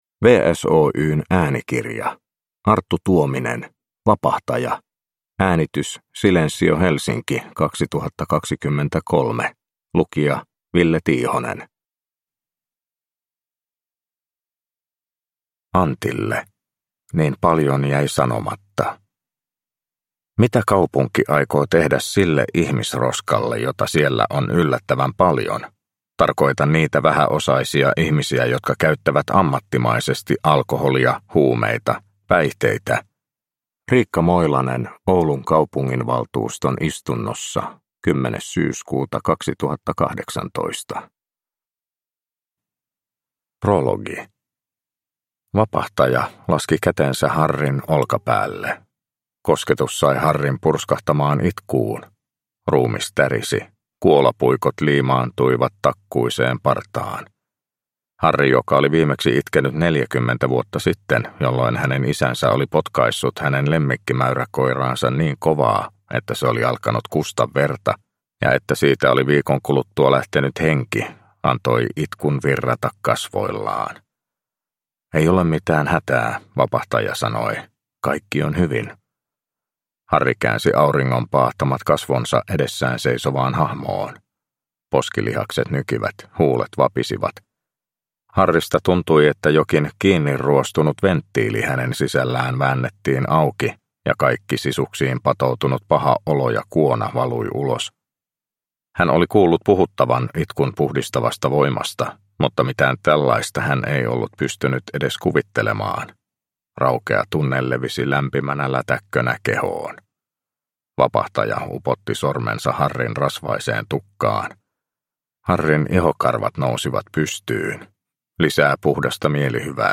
Vapahtaja – Ljudbok – Laddas ner